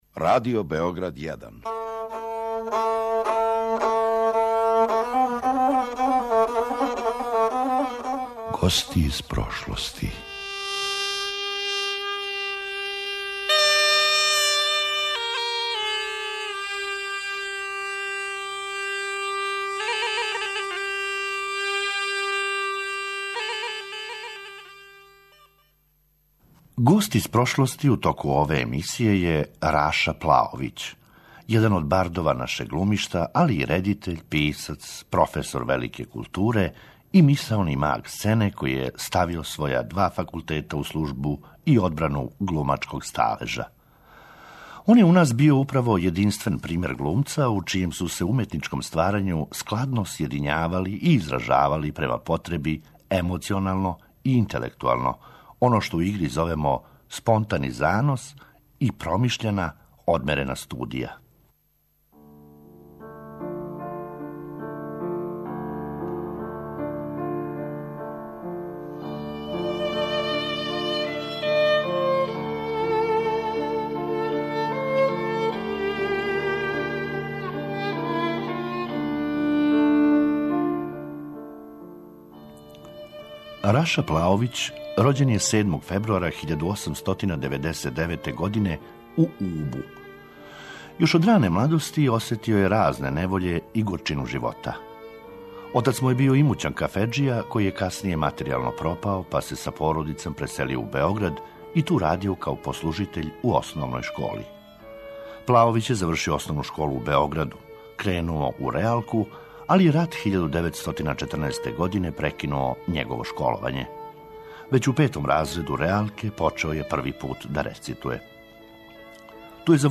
" У "Гостима из прошлости" - глумац Раша Плаовић.